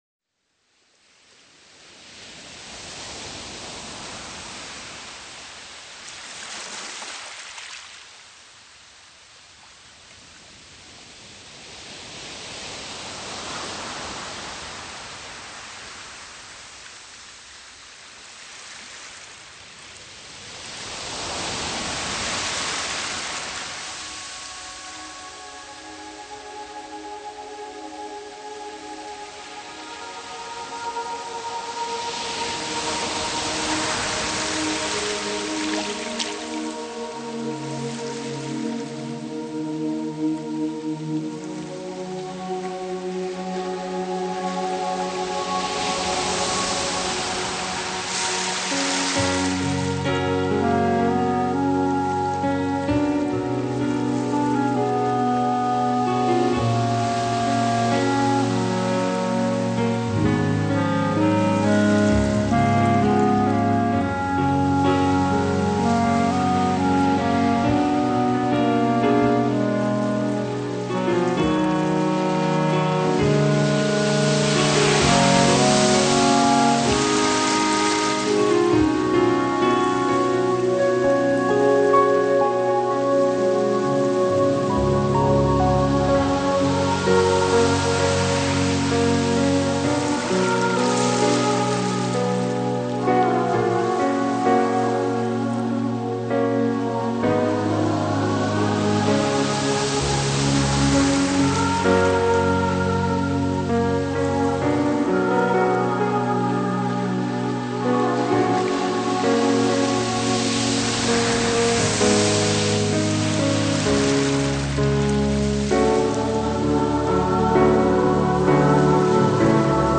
留存大自然最珍贵的声音，让所有人都能共享自然美妙的乐音。